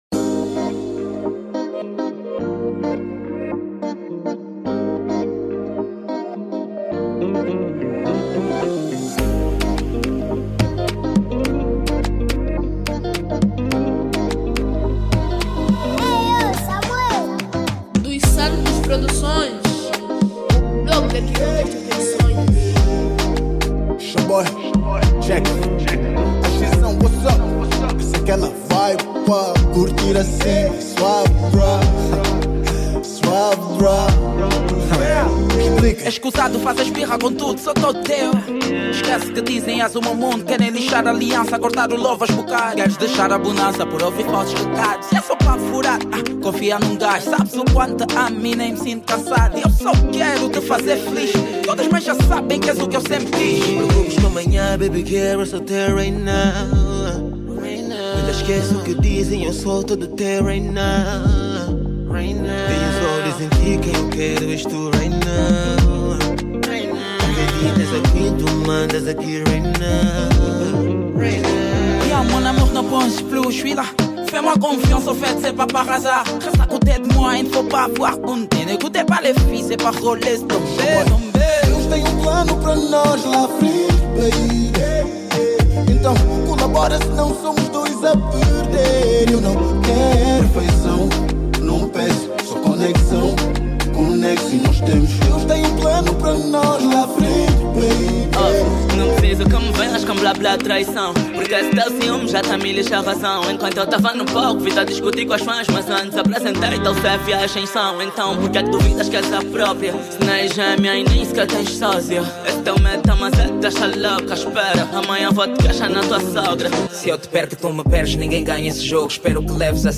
Categoria    Afro Pop